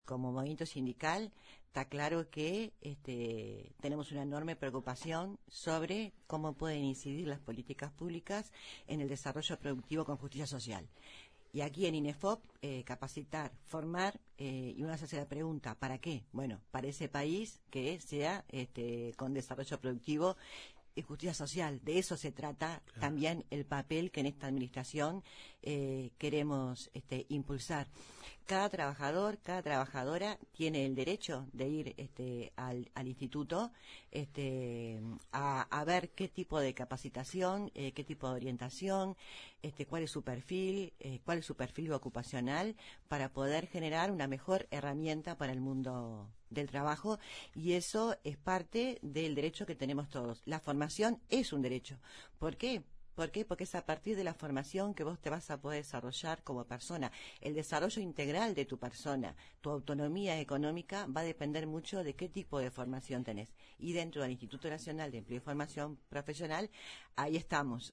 En diálogo con La Mañana de Uruguay, la directora de Inefop, Flor de Liz Feijoo, dijo que el Instituto “tiene herramientas para el emprendedurismo” nacional y que “cualquier política pública tiene que ser interinstitucional”, con el objetivo de acompañar a emprendimientos y pequeñas empresas en los 19 departamentos.